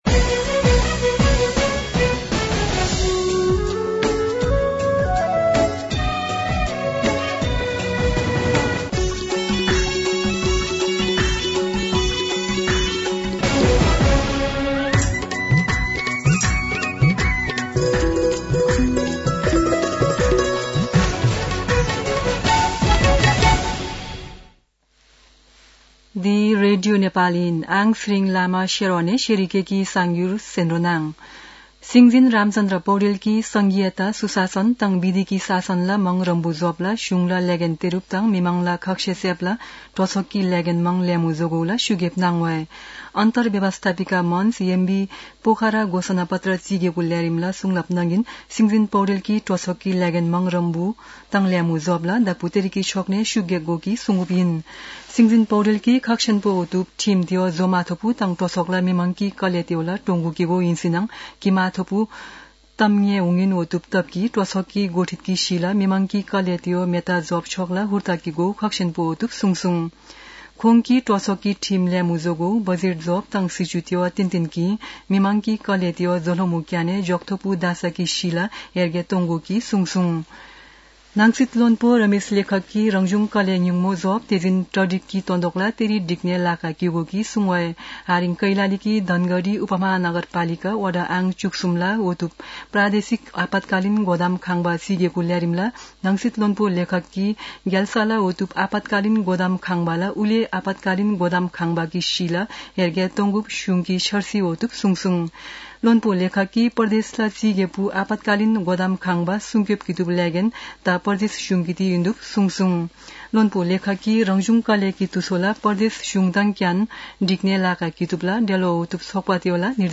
शेर्पा भाषाको समाचार : २८ फागुन , २०८१